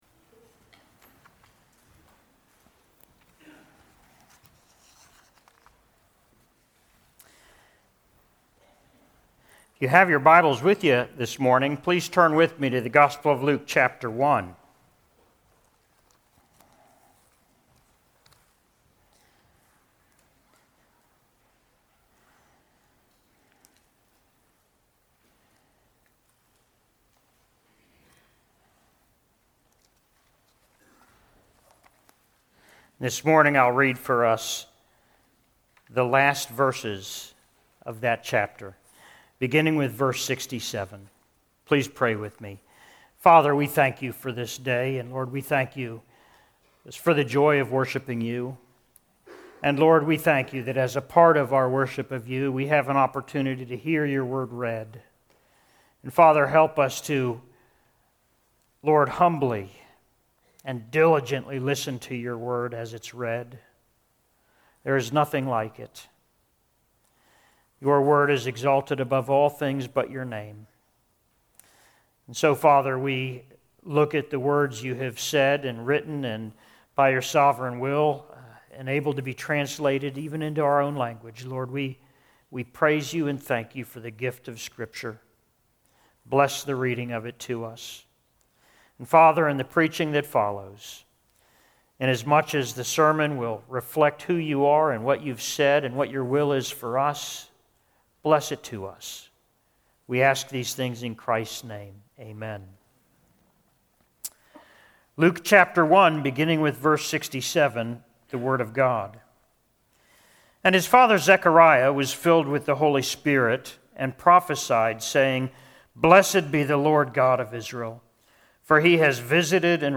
Sermon on Luke 1:67-80: Grace and Peace - Columbia Presbyterian Church